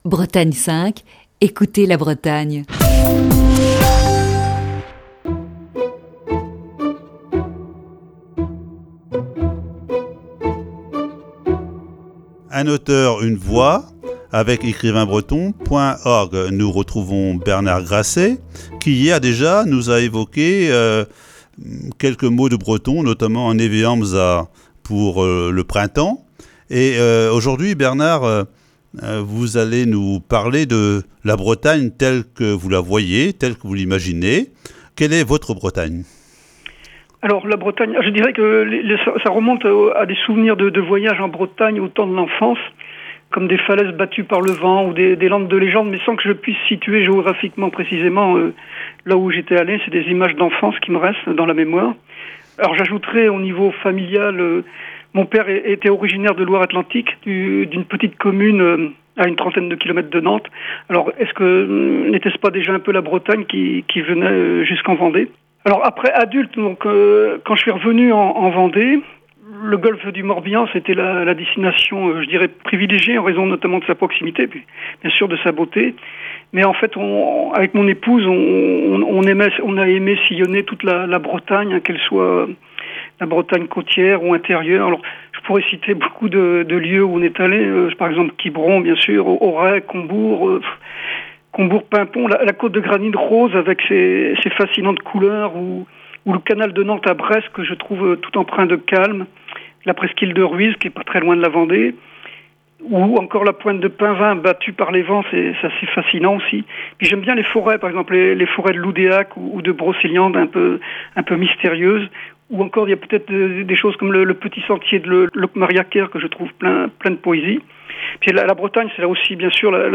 Voici ce mardi la deuxième partie de cette série d'entretiens.